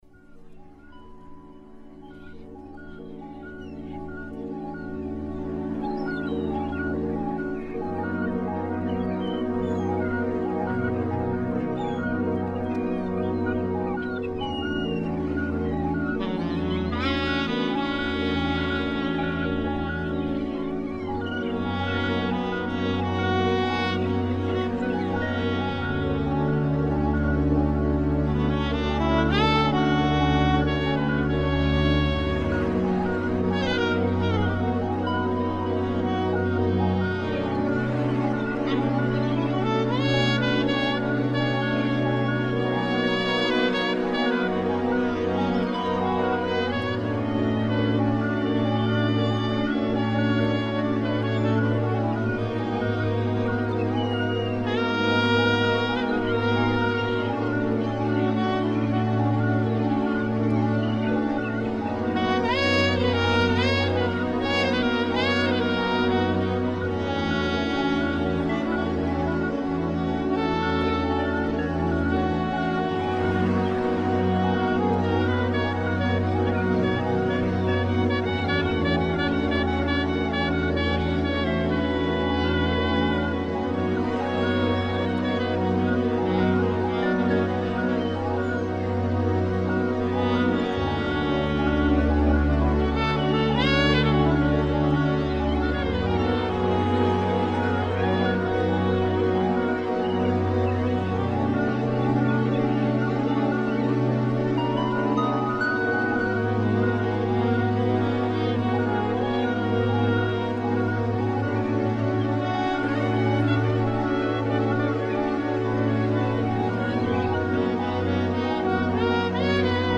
Playing the best in house and electronic music.